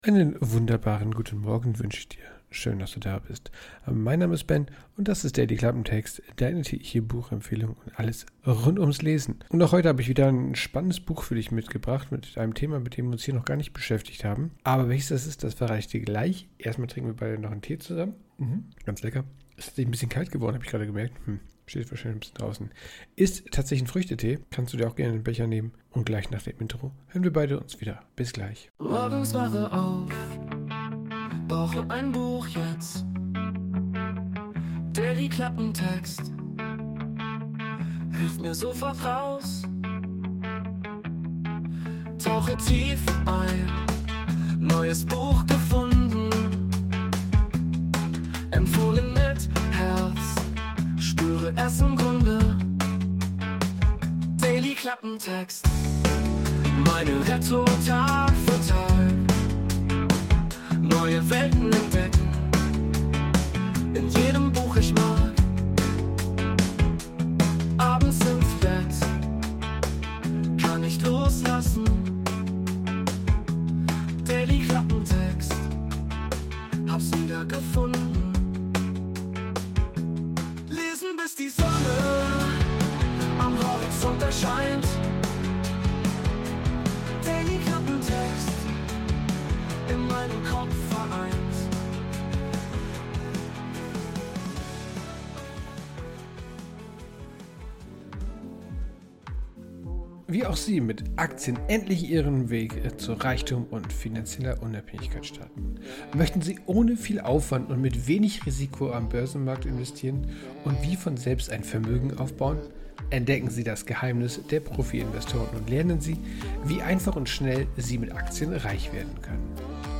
Buchbesprechung.
Intromusik: Wurde mit der KI Sonos erstellt.